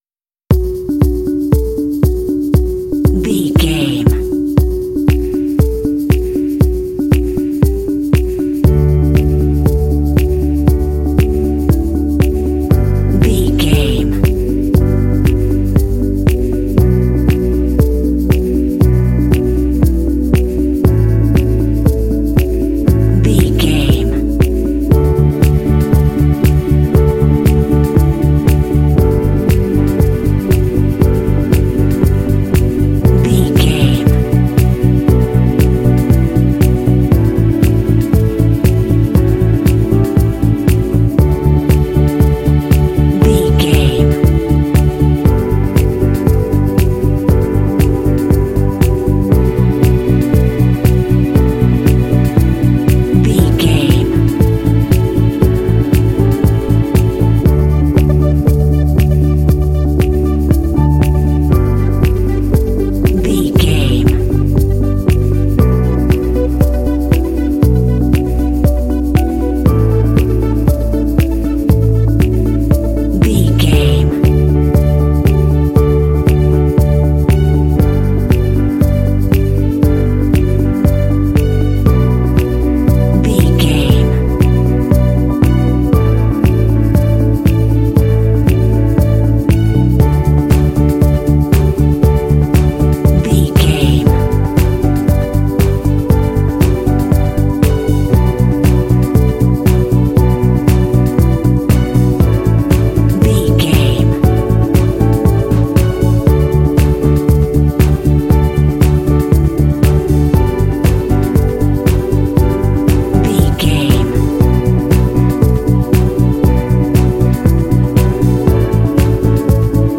Ionian/Major
D
uplifting
motivational
percussion
piano
synthesiser
bass guitar
strings
synth-pop
indie